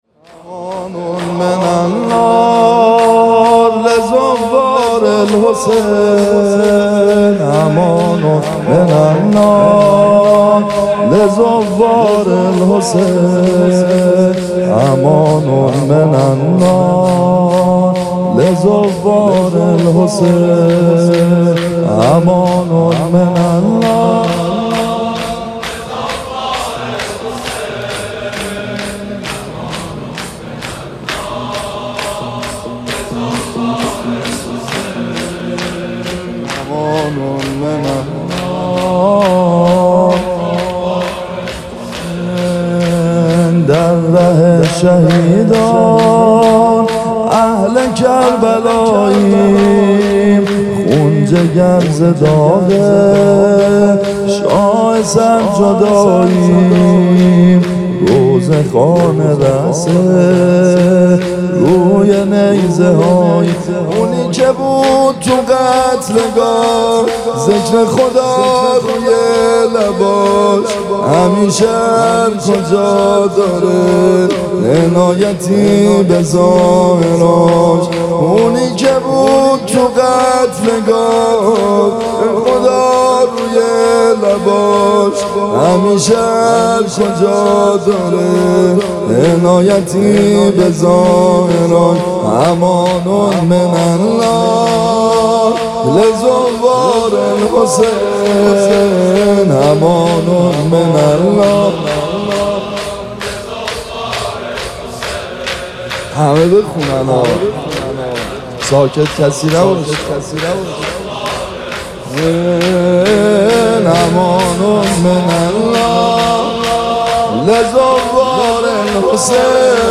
مداحی جدید
مراسم هفتگی فاطمیه هفتاد و دو تن